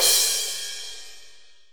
soft-hitfinish.wav